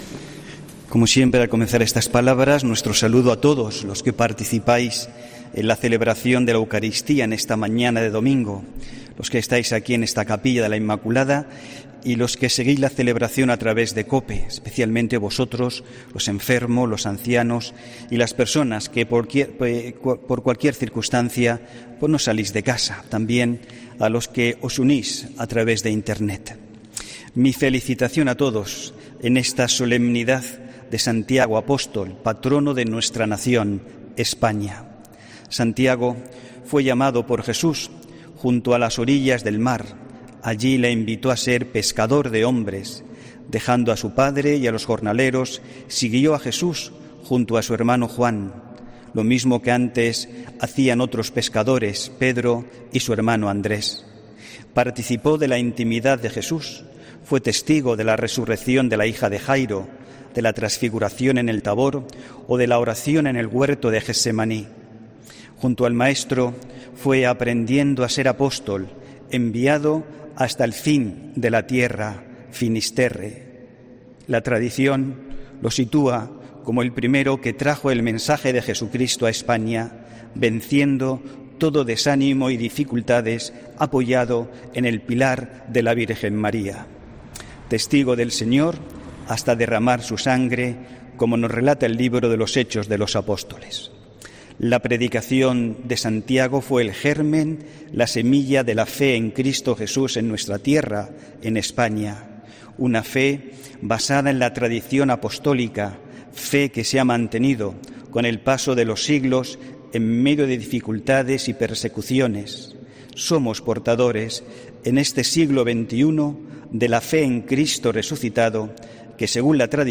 HOMILÍA 25 JULIO 2021